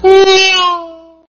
Nada notifikasi WA Ngeong
Kategori: Nada dering
nada-notifikasi-wa-ngeong-id-www_tiengdong_com.mp3